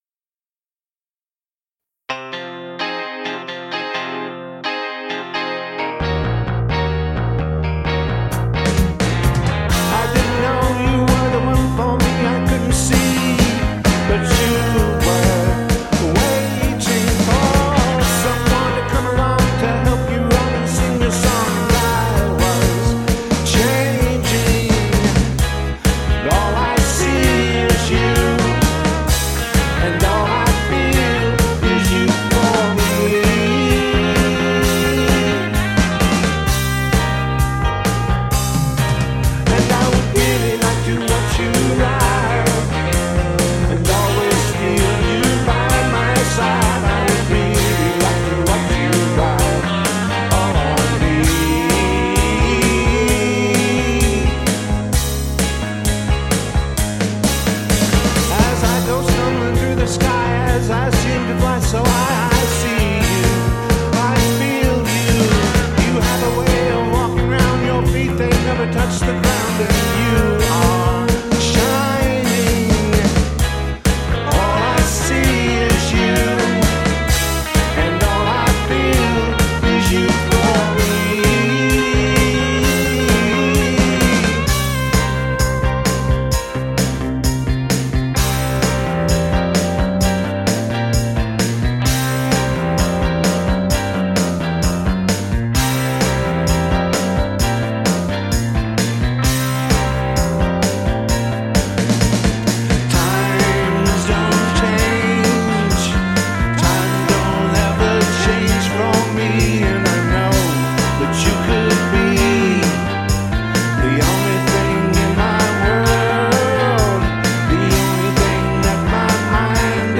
- Classic Rock Covers -
Psychedelic